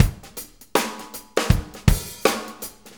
Shuffle Loop 23-08.wav